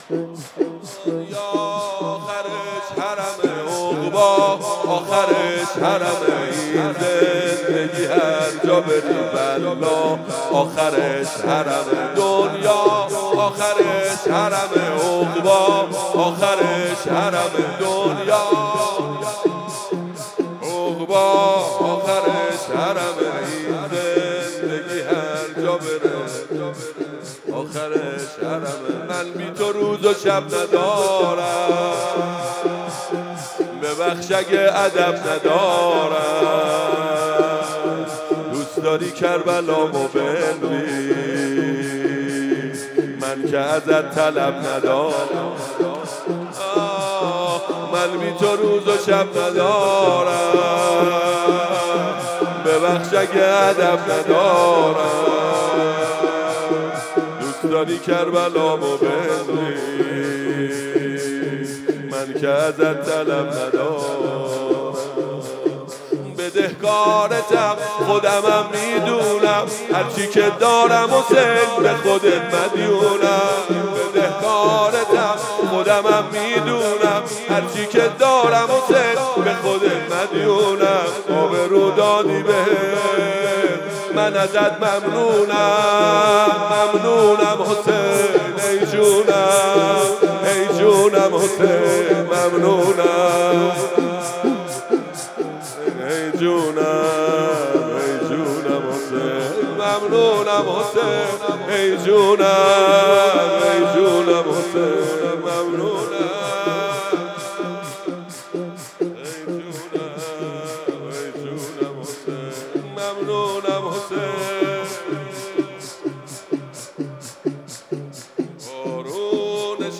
مداحی ها و نوحه های
مسجد ارک